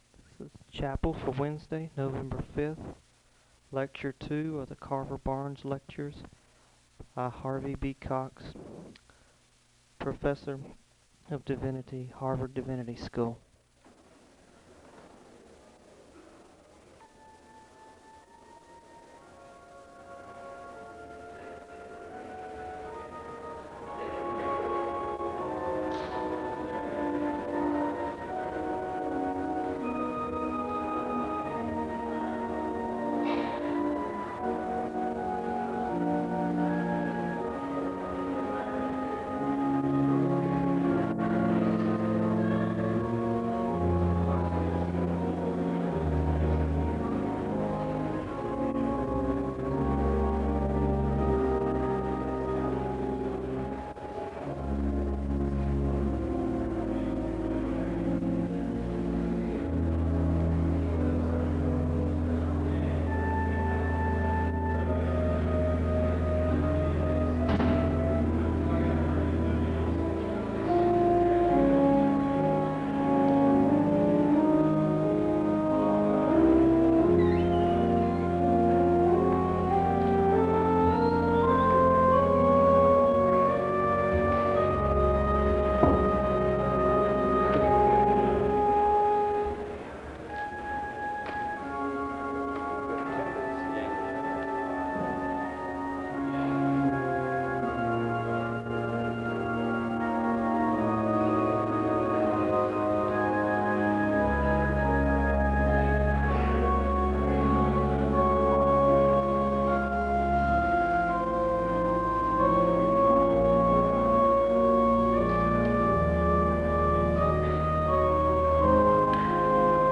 The service begins with organ music (0:00:00-0:05:16).
There is a moment of prayer (0:05:17-0:06:24). The guests are welcomed, and Harvey Cox is introduced as the Carver-Barnes Lecturer (0:06:25-0:07:36).
Thanks is expressed for Cox and a benediction is given (1:00:56-1:02:08).